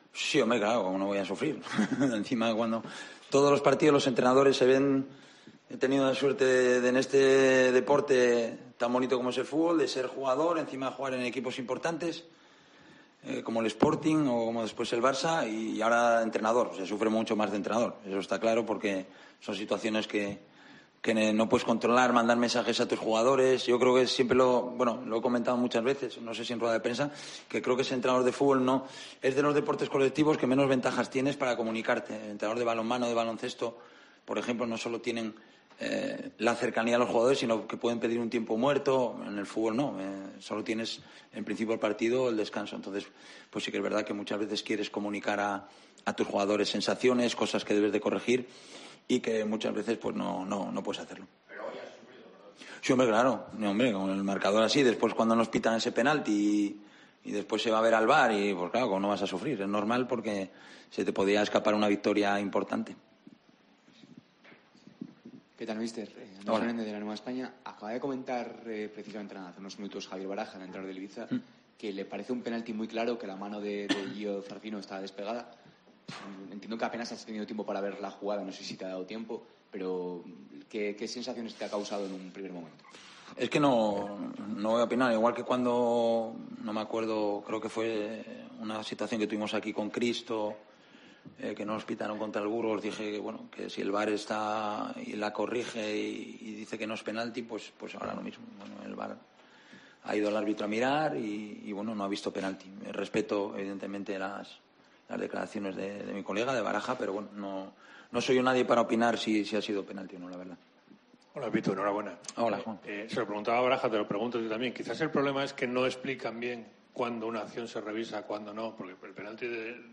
Abelardo priorizó en sala de prensa el resultado sobre el juego, y reconoció la importancia de la victoria.